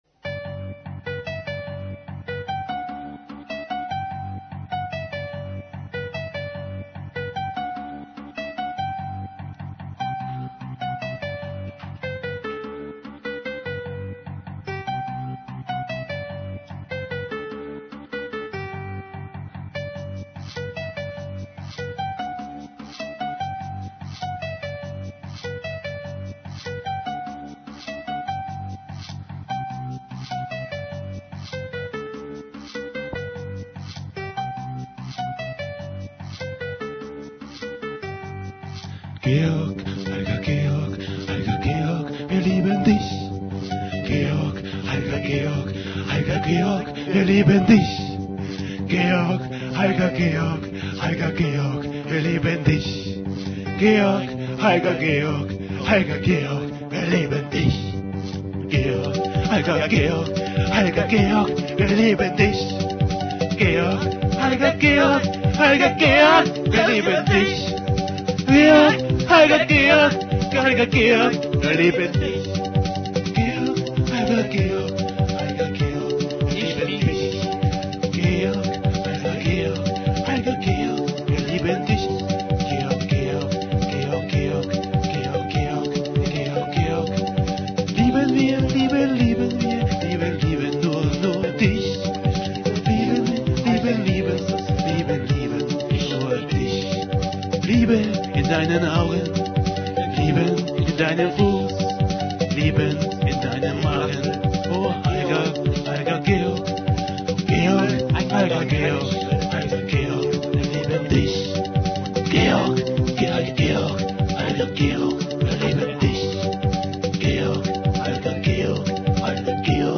(z.T leider schlechte Audioqualität)